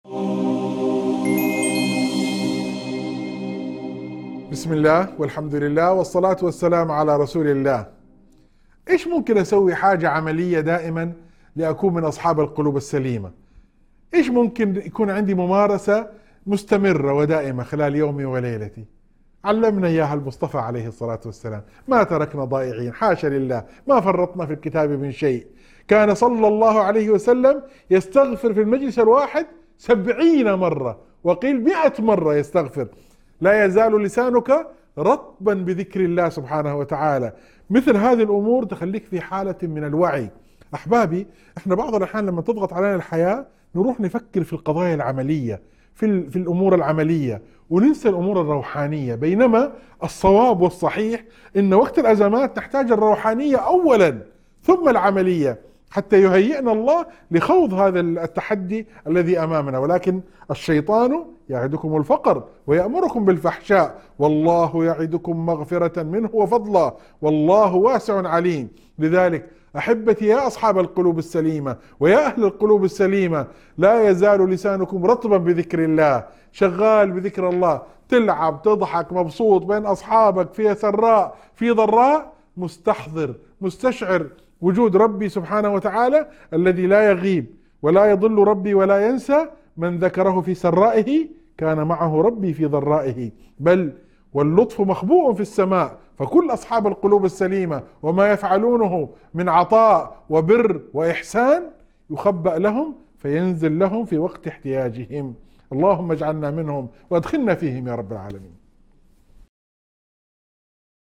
موعظة مؤثرة تحث على سلامة القلب وملازمته لذكر الله في السراء والضراء. تبيّن أهمية الجمع بين الجانب الروحاني والعَملي في الحياة، خاصة في أوقات الأزمات، مع التحذير من وساوس الشيطان.